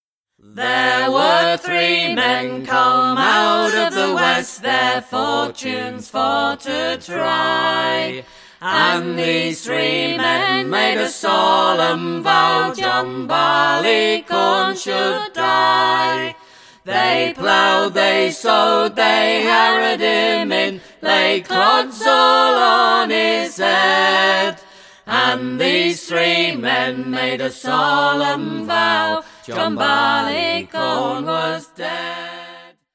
at Rooksmere Studios